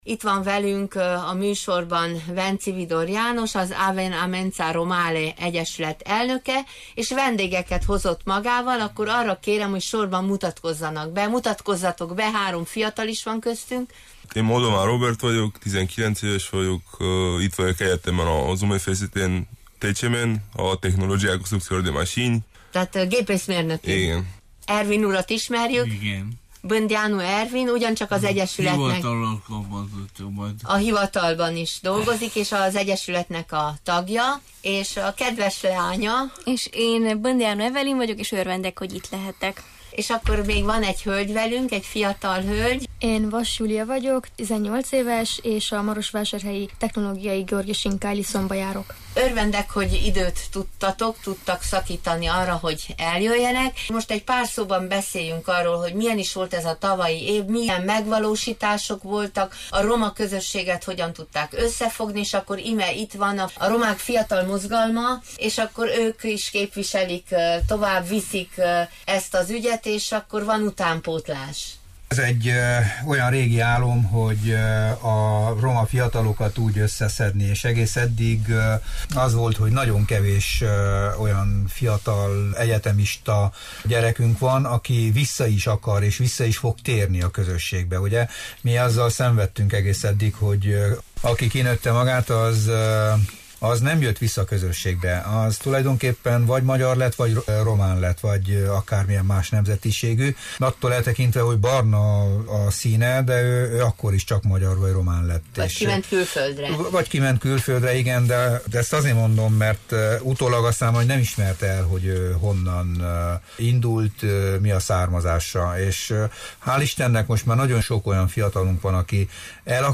A vasárnap reggel közvetített stúdióbeszélgetést itt hallgathatjuk vissza: